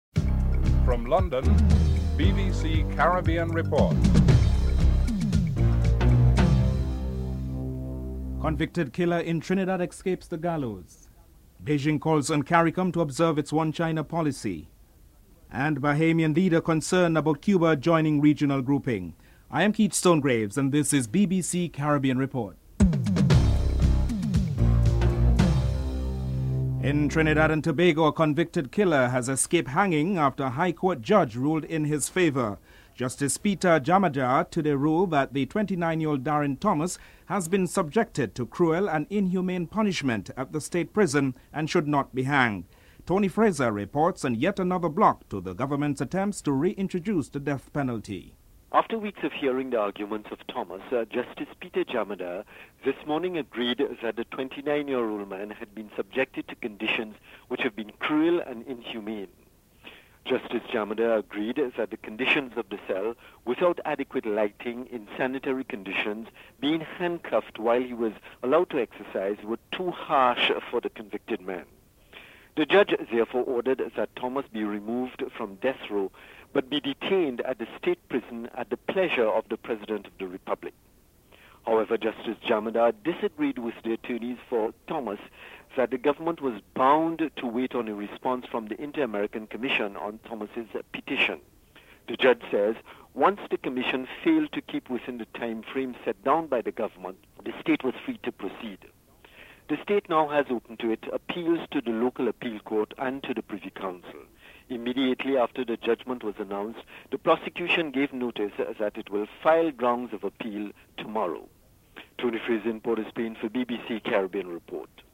Attorney General Ramesh Lawrence Maharaj is interviewed.